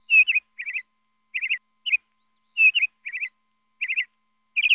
جلوه های صوتی
دانلود صدای طاووس از ساعد نیوز با لینک مستقیم و کیفیت بالا
برچسب: دانلود آهنگ های افکت صوتی انسان و موجودات زنده